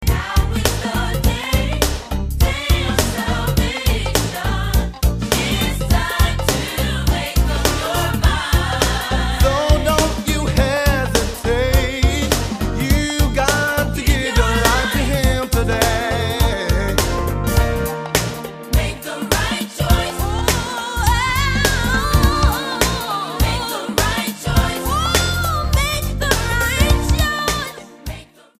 STYLE: Classical
soprano